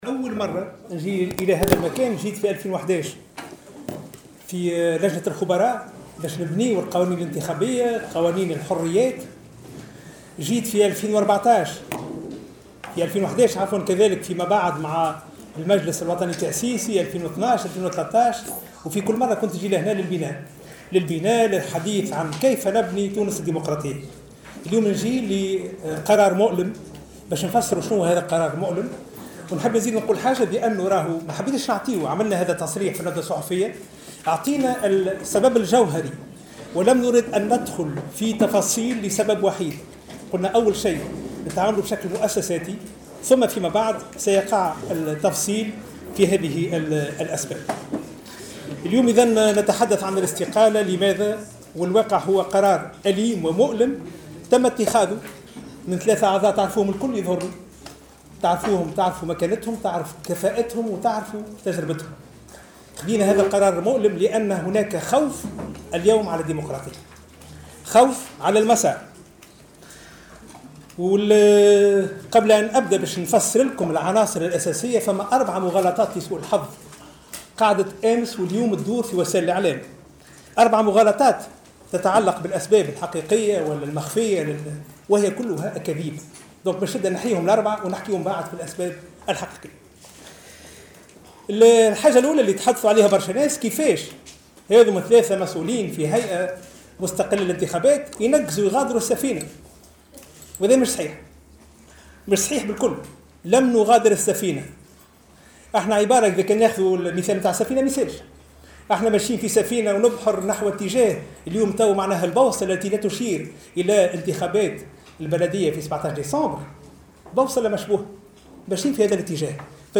وأكد صرصار في تصريح لمراسل الجوهرة أف أم، على هامش جلسة الإسمتاع إليه من قبل لجنة النظام الداخلي والحصانة والقوانين البرلمانية والقوانين الانتخابية اليوم الأربعاء 10 ماي 2017، أنه سيواصل، رفقة الأعضاء المستقيلين، العمل لمواصلة الاضطلاع بمهامهم.